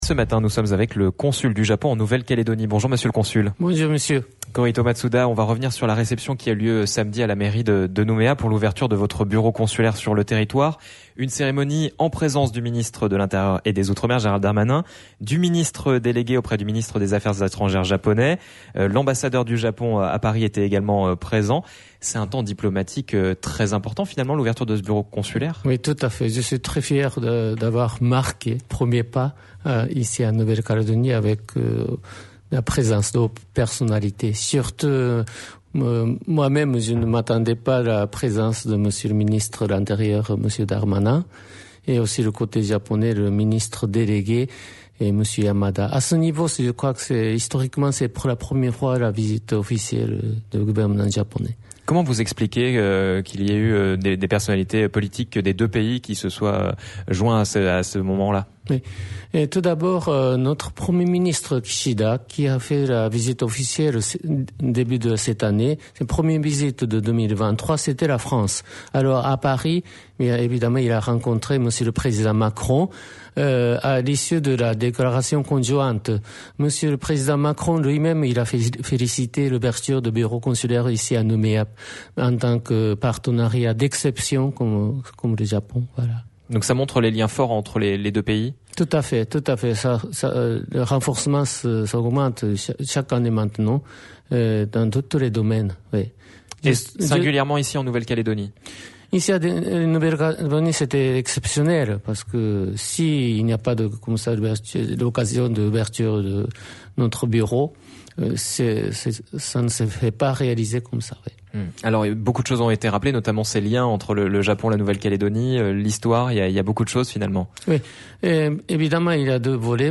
reçoit Korehito Masuda, consul du Japon en Nouvelle-Calédonie